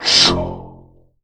SSSSHHHM.wav